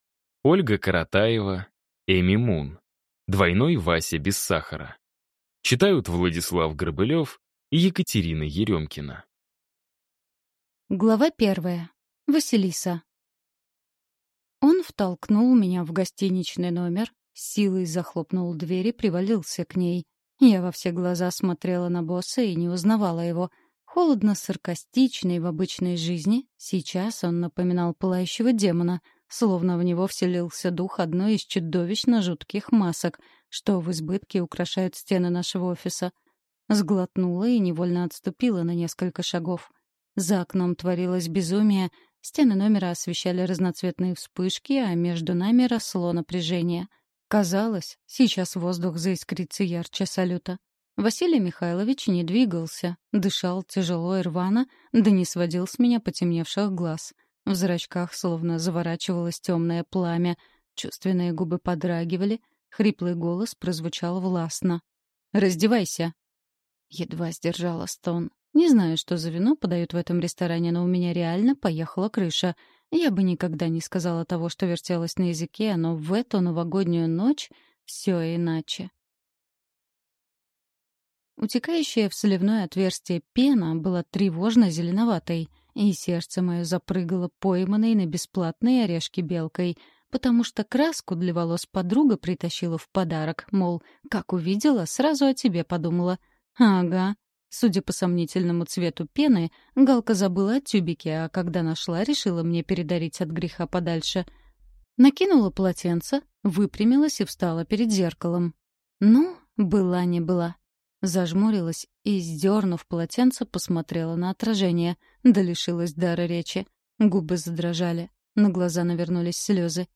Аудиокнига Двойной Вася без сахара | Библиотека аудиокниг
Прослушать и бесплатно скачать фрагмент аудиокниги